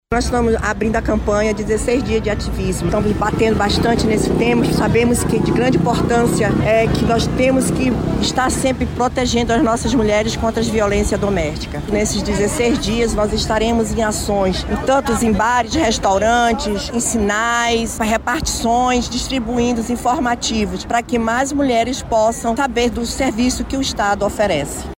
A secretária executiva de Políticas para Mulheres da Sejusc, Maricilia Costa, destaca as atividades que serão realizadas durante a campanha.